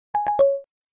Голосовой помощник - Альтернативный вариант